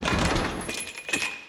SFX_Motorcycle_PickUp_04.wav